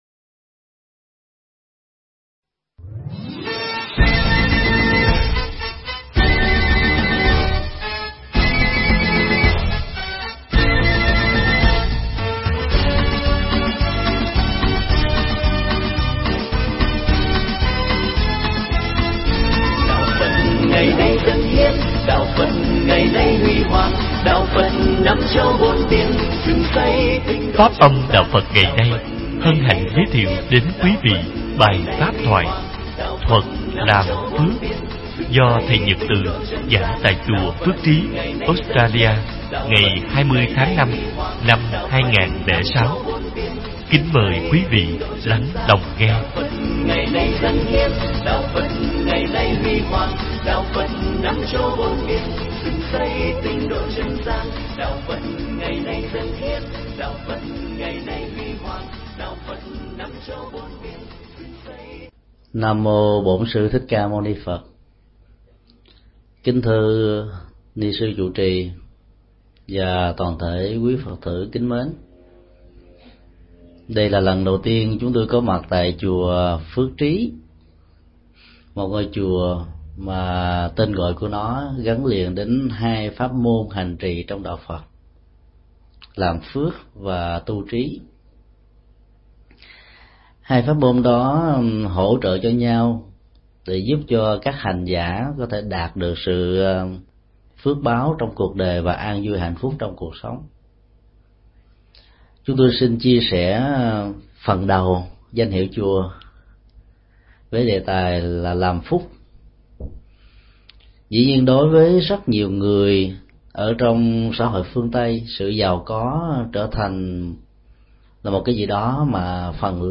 Mp3 Thuyết pháp Thuật Làm Phước – thầy Thích Nhật Từ giảng tại Chùa Phước Trí – Australia, ngày 20 tháng 05 năm 2006...